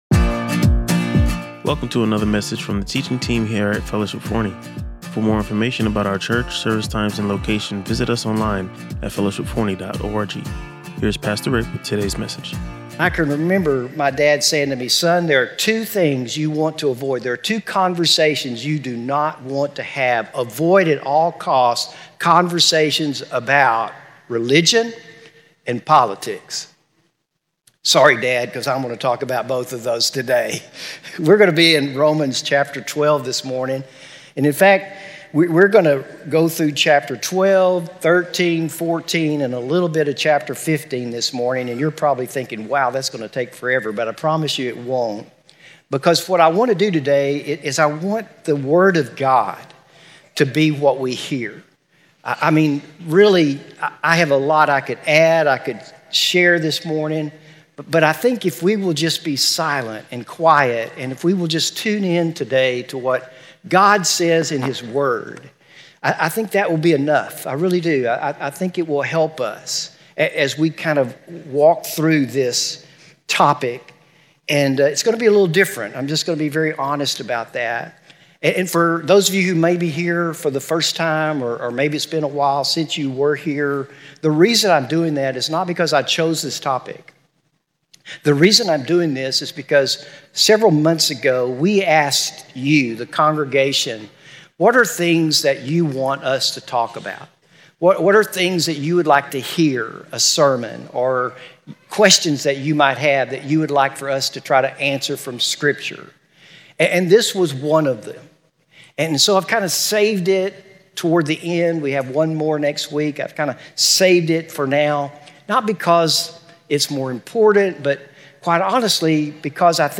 Listen to or watch the full sermon and discover how to apply these biblical principles in today’s divided world.